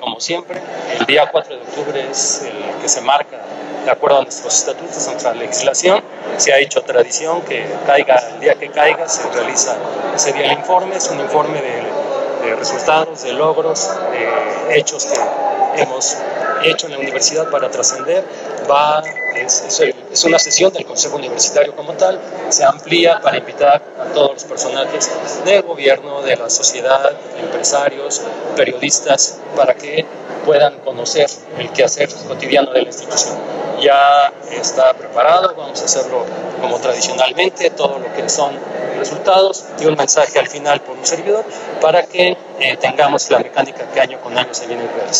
En entrevista, abundó que ya se efectuó la renovación de descuento para las y los estudiantes que hacen uso del Sistema de Transporte Articulado (RUTA), lo que resulta un gran apoyo para sus traslados.